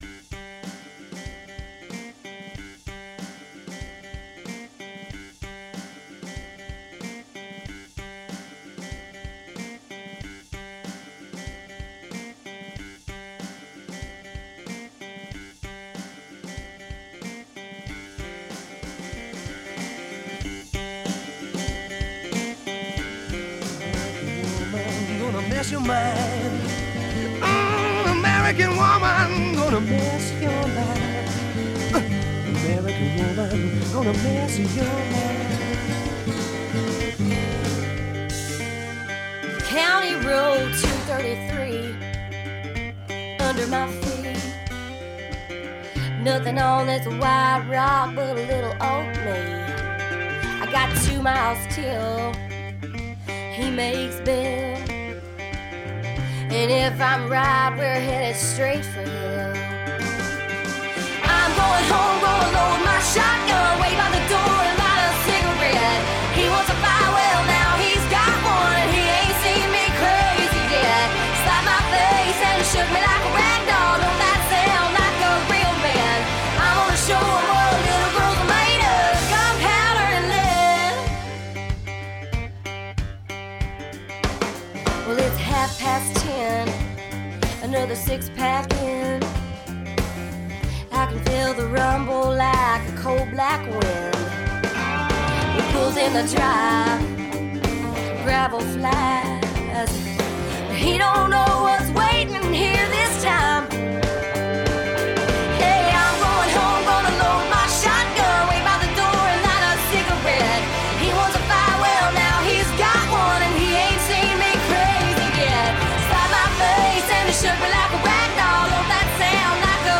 Genres: Country, Hip Hop, Rock, Top 40